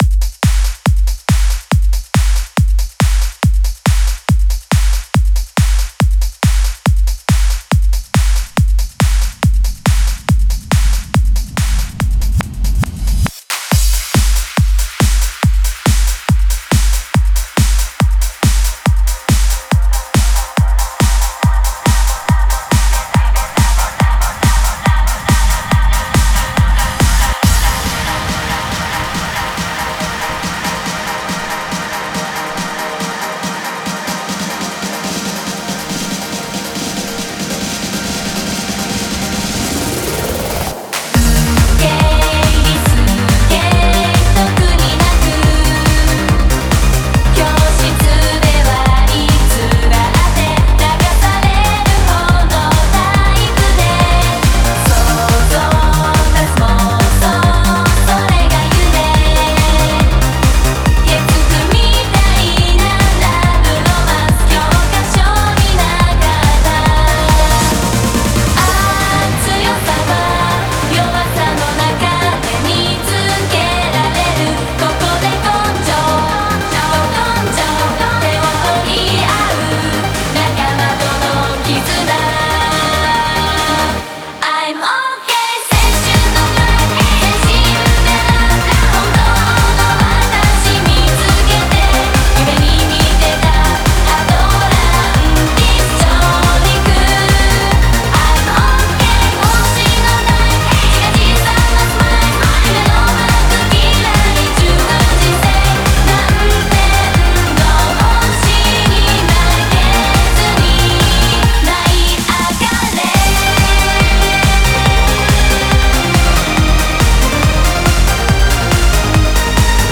Genre : Trance / J-Pop
BPM : 140 BPM
Release Type : Bootleg / Digital